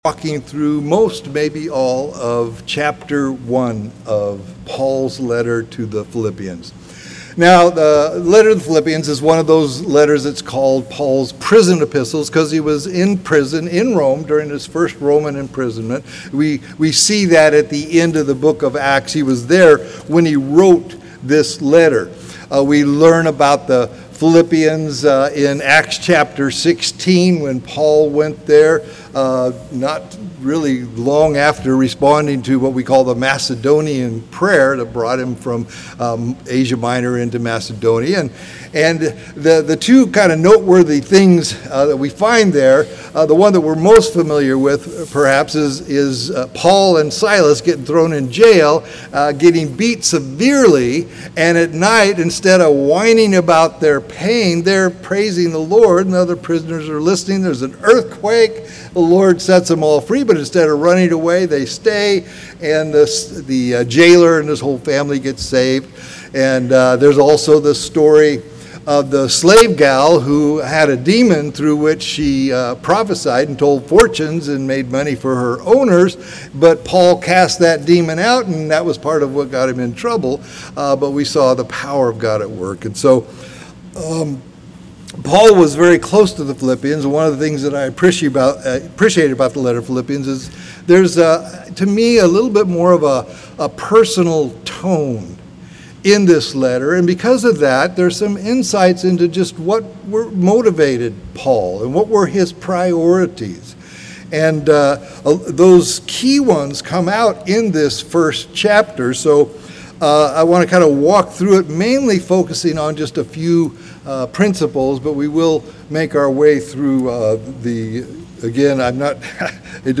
CCS Sermons